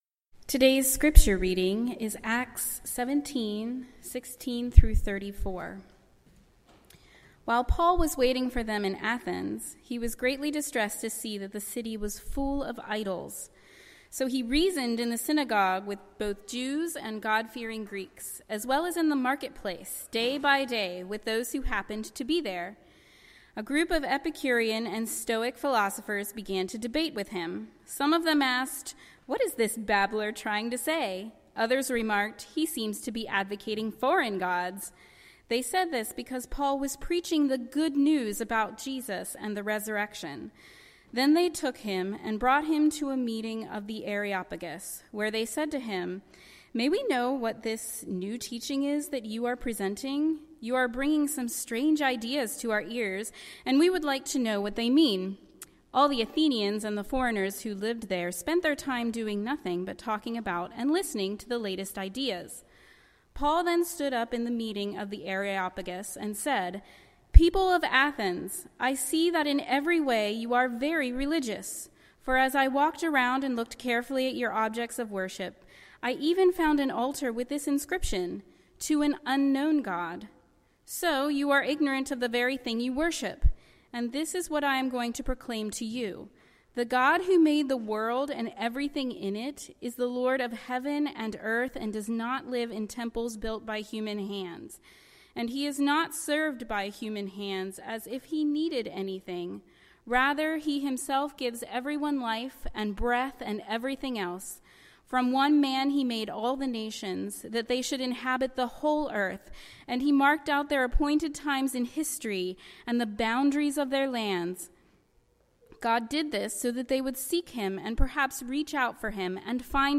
Guest Preachers